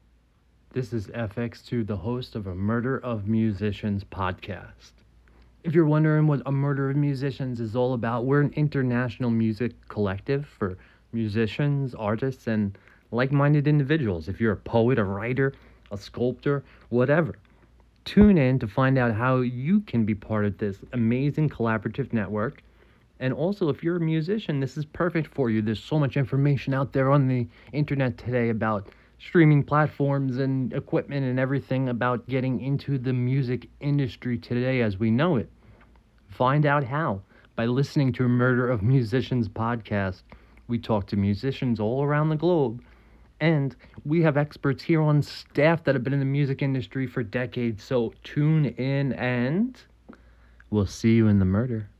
Genres: Arts, Music, Music Interviews, Performing Arts
Trailer: